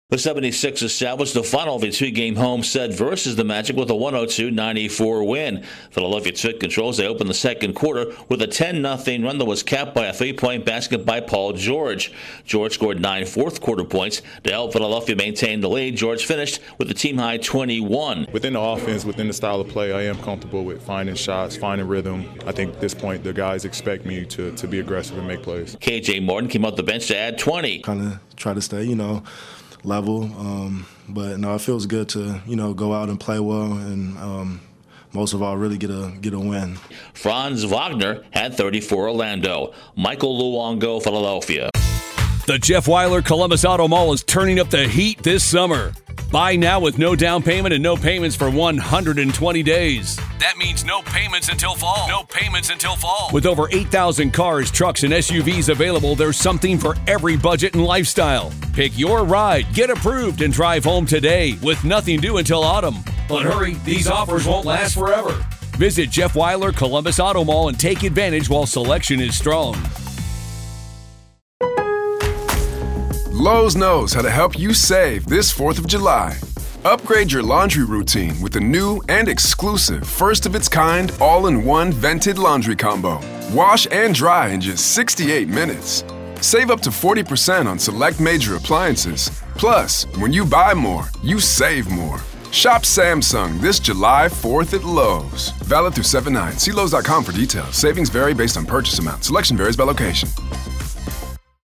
The 76ers pull away in the second period and hold off the Magic. Correspondent